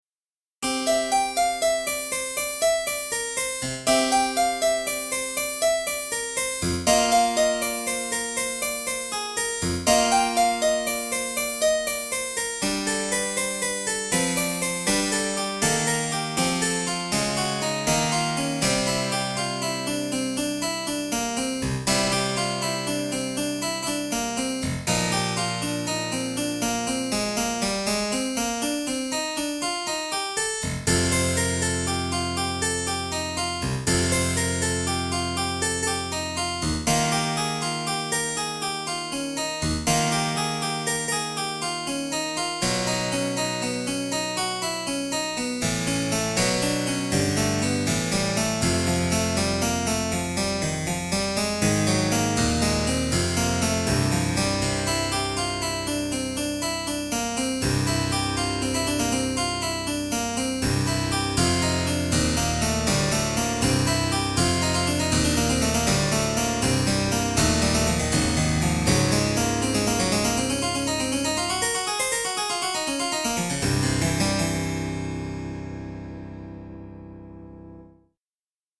Keyboard / 2007